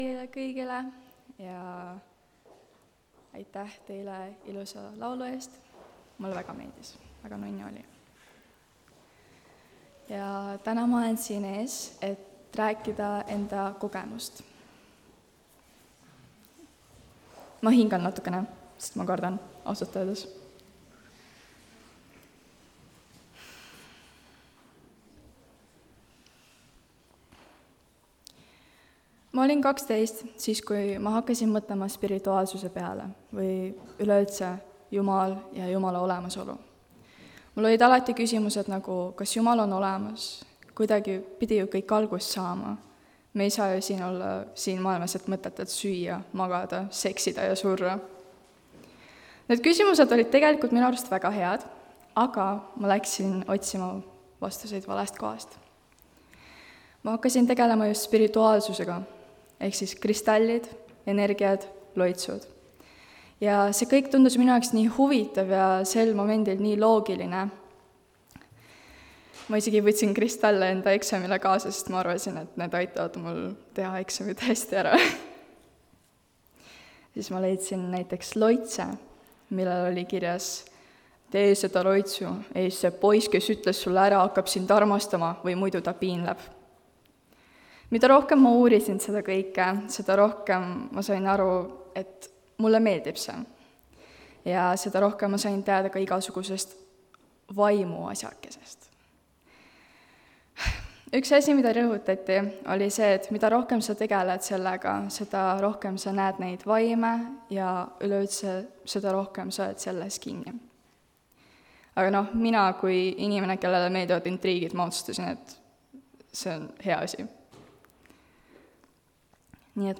Noorte Jumalateenistus: Tunnistuste koosolek (Tallinnas)